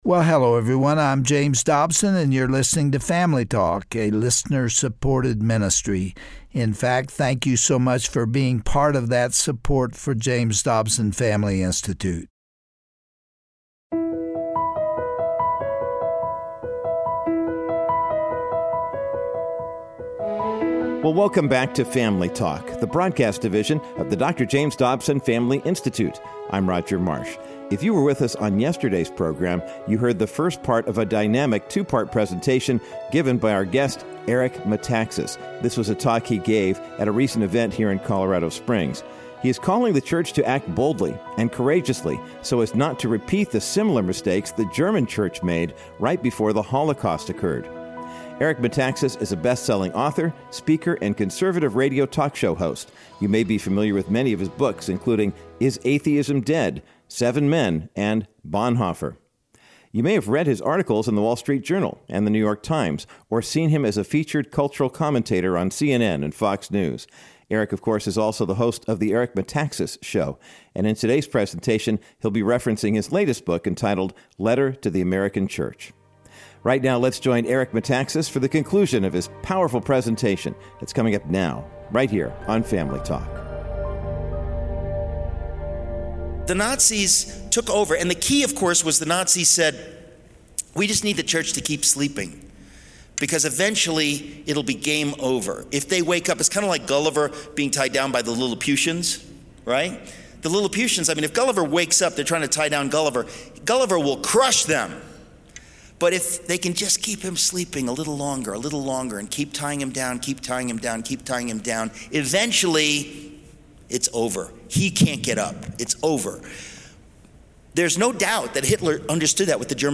On today’s edition of Family Talk, author Eric Metaxas concludes his riveting message about America and what makes her great! Eric also warns that much like Gulliver in the land of the Lilliputians, we may possess the ability to crush the enemy, but if we are caught sleeping too long, we will be bound into submission by the moral decay that is sweeping our nation.
Host Dr. James Dobson